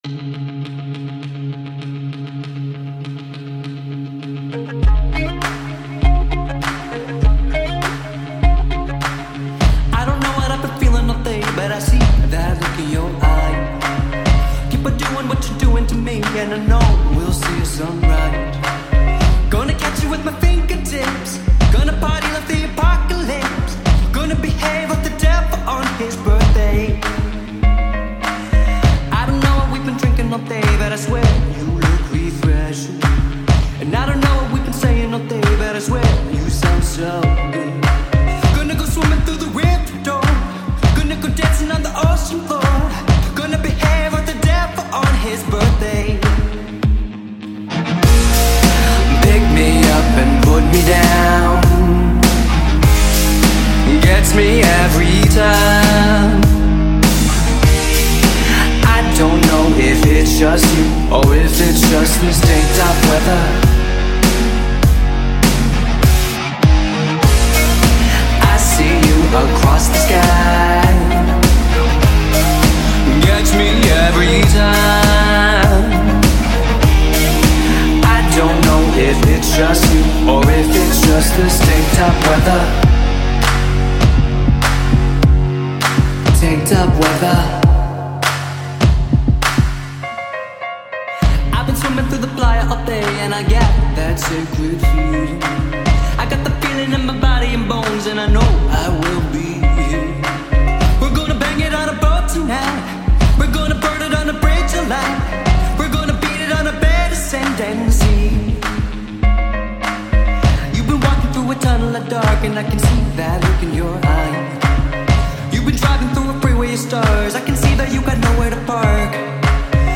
NuDisco/Rock